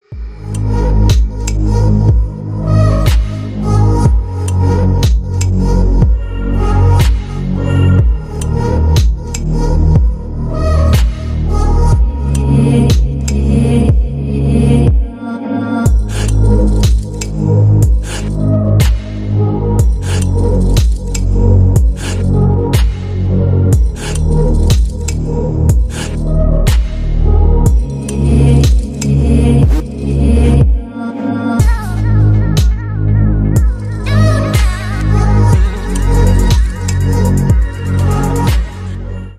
• Качество: 320 kbps, Stereo
Поп Музыка
тихие
без слов